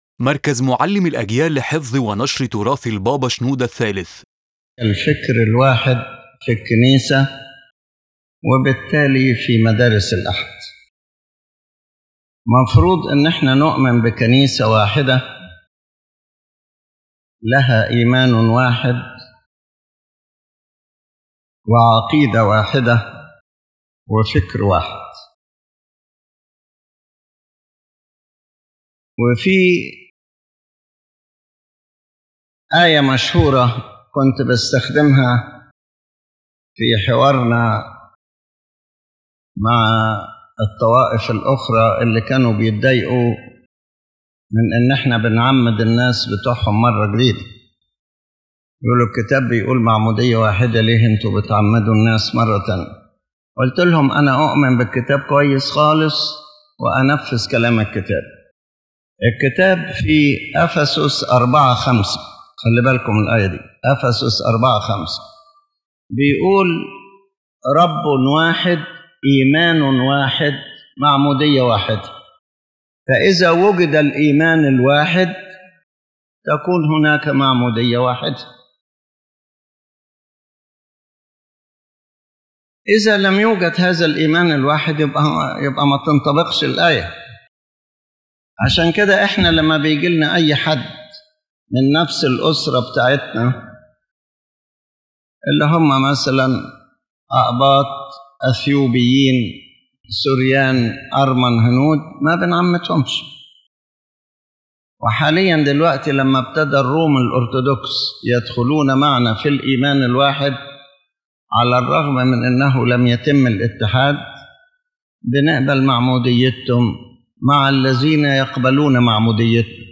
Lecture Summary